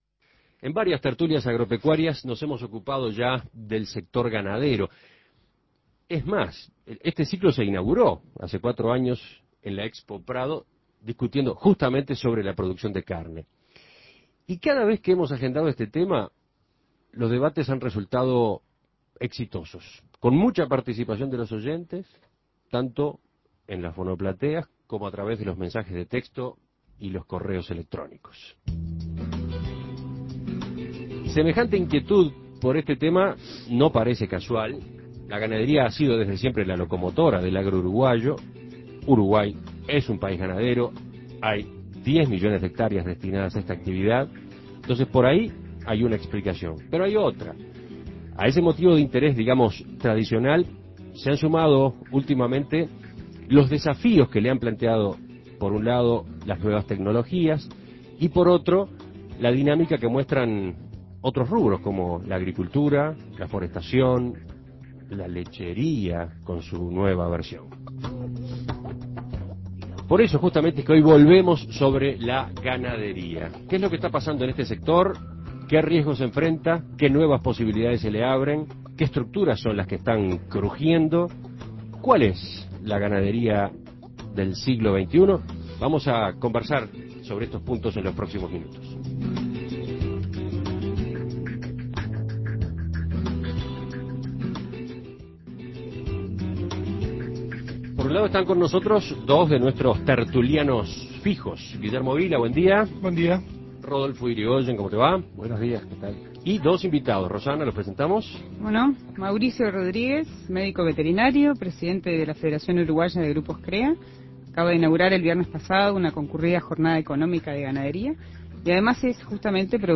Con una tradición tan antigua como el propio país y 10 millones de hectáreas destinadas a tal actividad, la ganadería es el motor del agro uruguayo. En los últimos años, el avance de la tecnología y la dinámica de otras actividades en crecimiento proponen nuevos desafíos. Para discutir sobre cuál es la ganadería del siglo XXI, la Tertulia Agropecuaria reunió a los tertulianos habituales